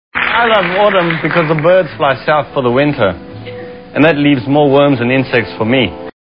Category: Television   Right: Personal
Tags: David Letterman Letterman David Letterman clips tv talk show great audio clips